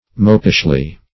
Meaning of mopishly. mopishly synonyms, pronunciation, spelling and more from Free Dictionary.